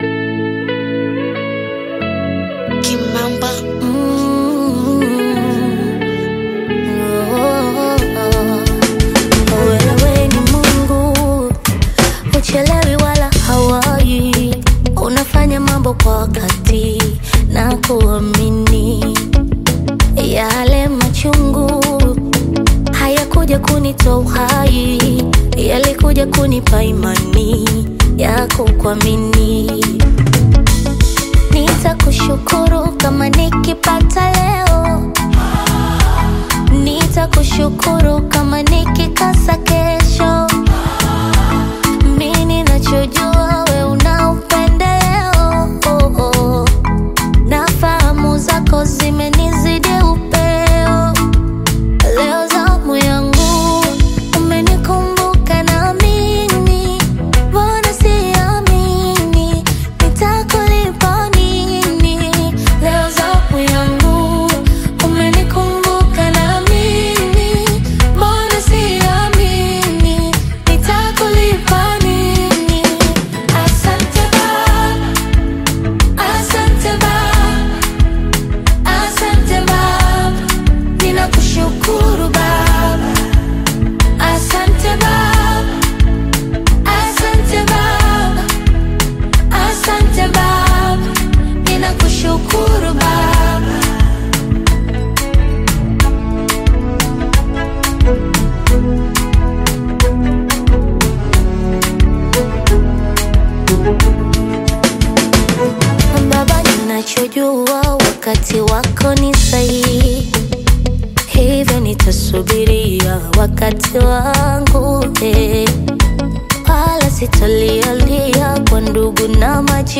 AudioGospel
heartfelt Afro-Pop/Bongo Flava single
expressive vocals and warm Swahili lyrics